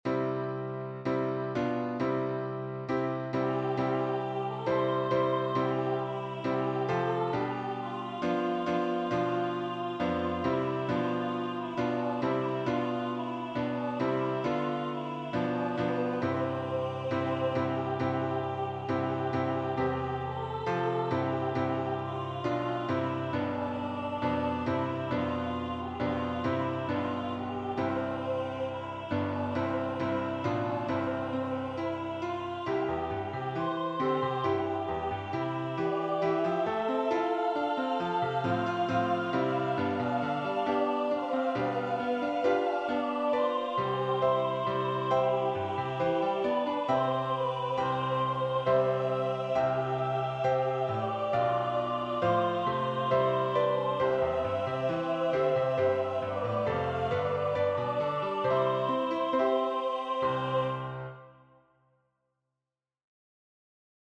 Voicing/Instrumentation: Primary Children/Primary Solo
Includes Vocal Obbligato/Descant